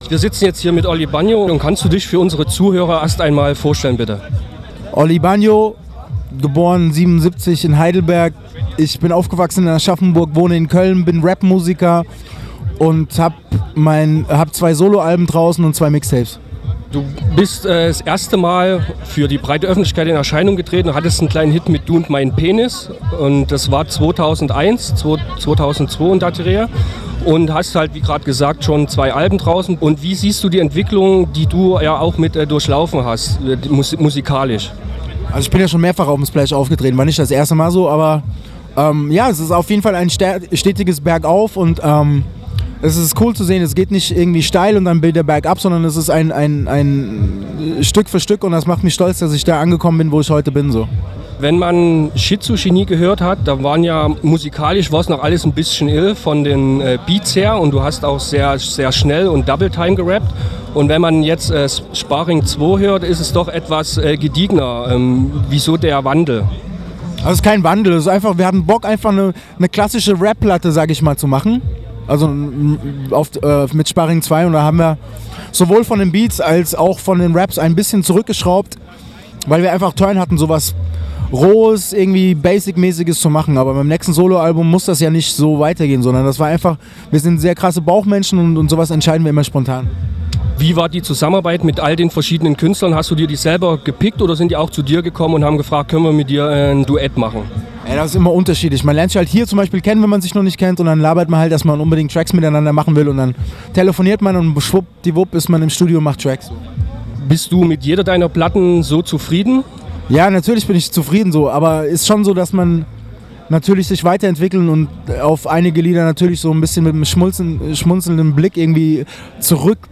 Interview mit Olli Banjo beim Splash! 2006, Rückblick Splash!, Infos Save Splash!, VA Tips, Musik
Olli Banjo Interview Splash! 2006